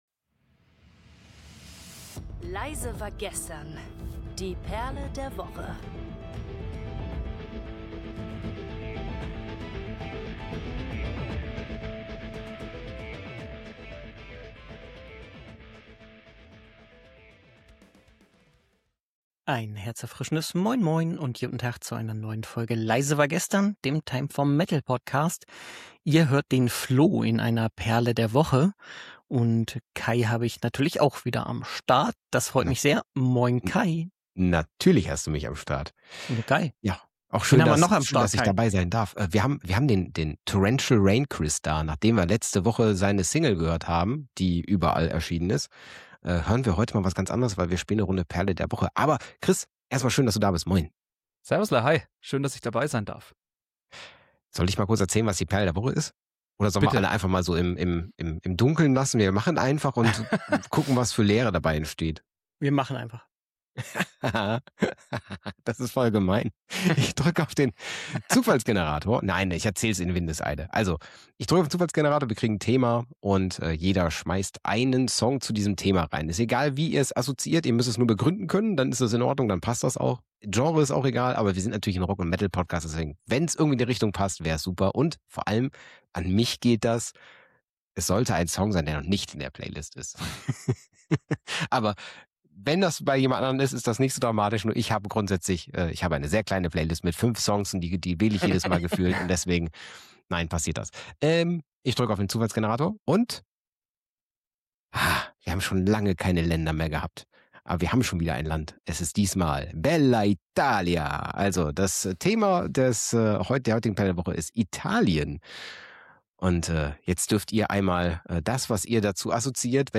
Natürlich darf auch der typische Perle-der-Woche-Moment nicht fehlen: Am Ende entscheidet wieder der Zufall über den finalen Song der Episode.